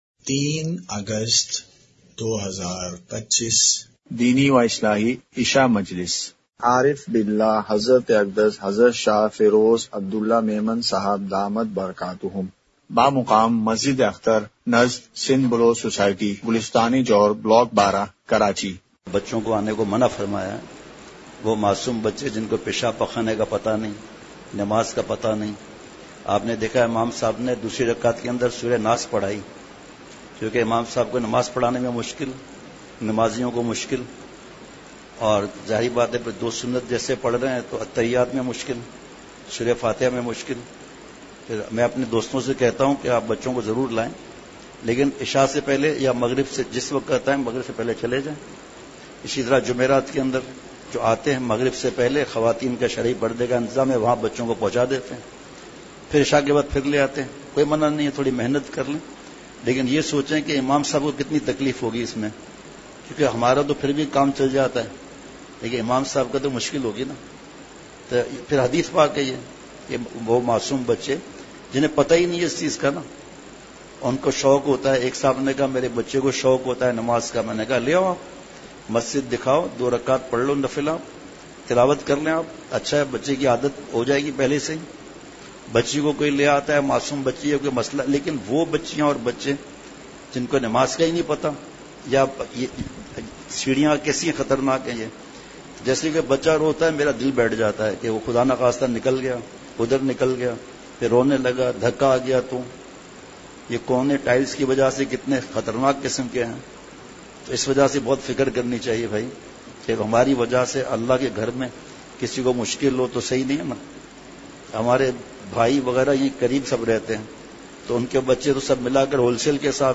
عشاء مجلس ۳ / اگست ۲۵ء:تصویر کشی کے گناہ سے کیسے بچا جائے؟
اصلاحی مجلس کی جھلکیاں *مقام:مسجد اختر نزد سندھ بلوچ سوسائٹی گلستانِ جوہر کراچی*